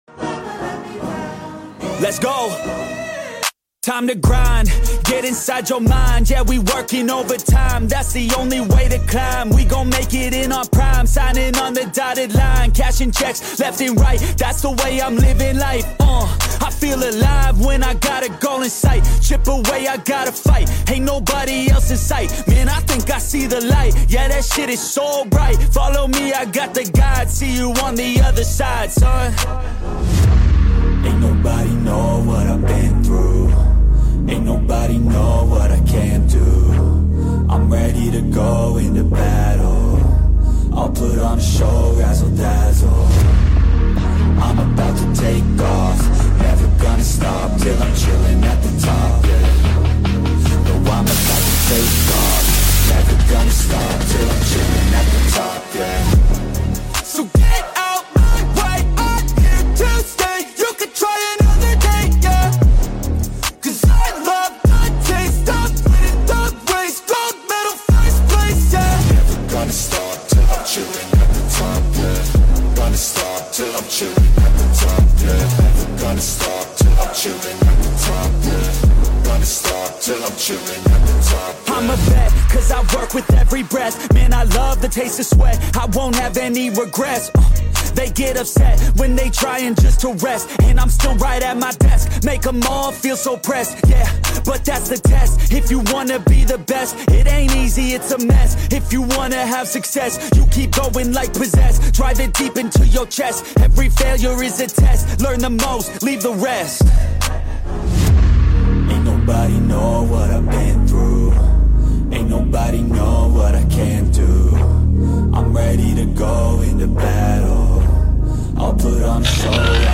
💥 8 WORLD RECORDS SMASHED 🔊 Loudest SPL Car of the Day 💣 Loudest Bass Boxing Car of the Day 👑 We Lead.